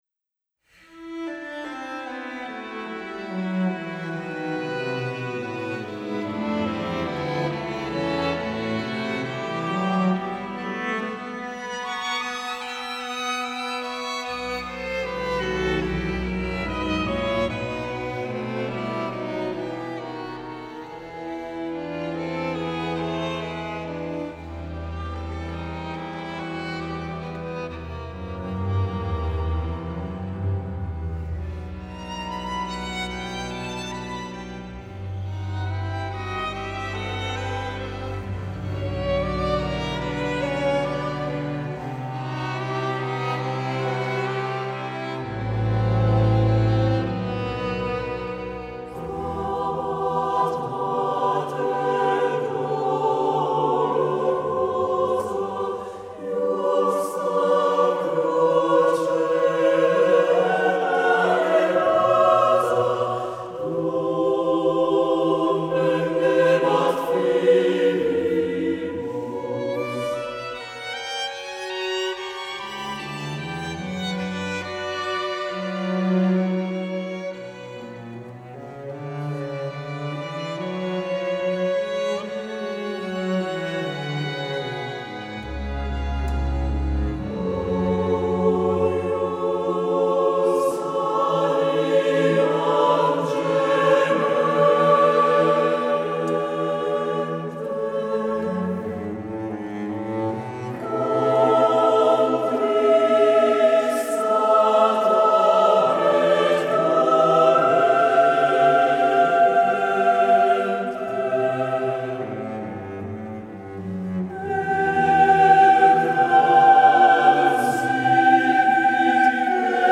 per coro a 6 voci a cappella op. 51 (Kind. 169)
Organico: soprano, quintetto d’archi
Organico: mezzosoprano, quartetto d’archi
quintetto d’archi